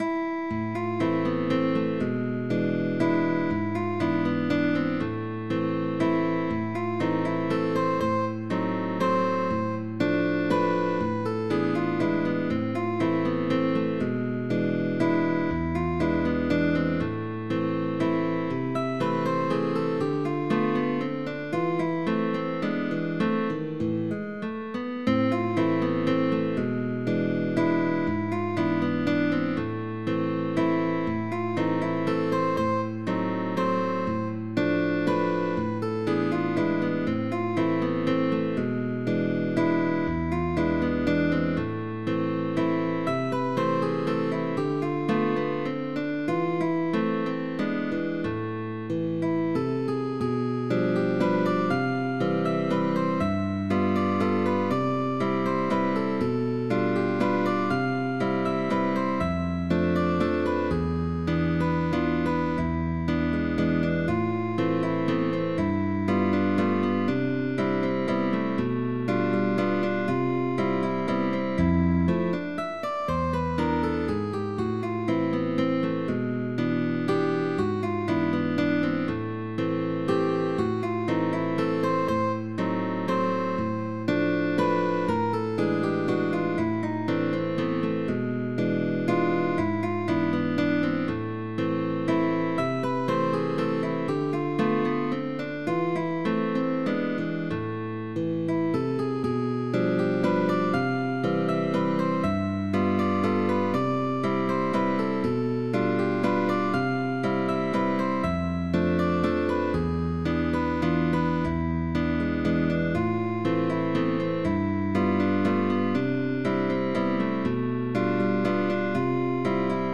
It is marked with an overall tempo of Moderato.